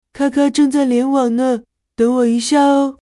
卡皮巴拉板载语音
配网完成后，开机后播报.MP3